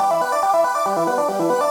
Index of /musicradar/shimmer-and-sparkle-samples/140bpm
SaS_Arp02_140-E.wav